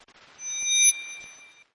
Tesla Lock Sound Shine
Shine sound
(This is a lofi preview version. The downloadable version will be in full quality)
Here is how to use the custom sound effect on your car’s external speaker (PWS).
JM_Tesla_Lock-Sound_Shine_Watermark.mp3